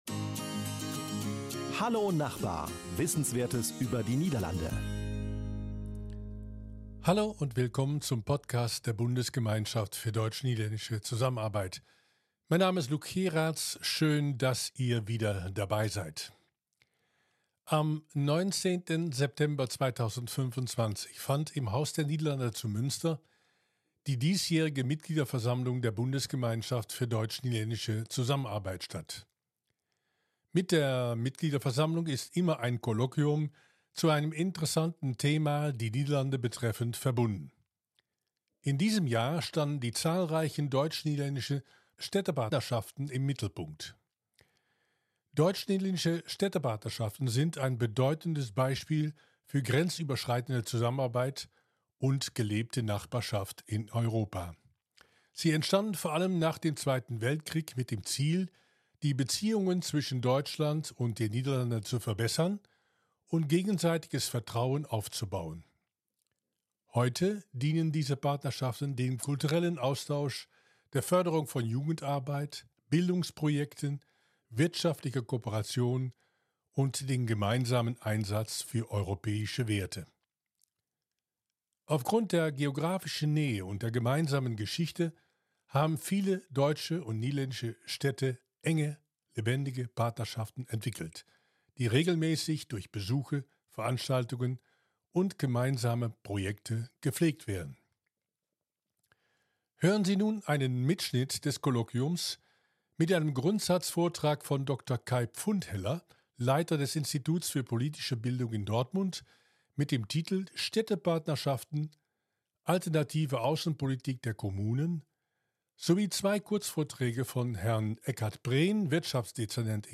Beschreibung vor 6 Monaten Am 19. September 2025 fand im Haus der Niederlande zu Münster die diesjährige Mitgliederversammlung der Bundesgemeinschaft für deutsch-niederländische Zusammenarbeit statt.